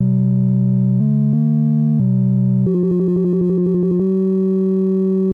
playing in the opening cutscene
replaced with non-crackly version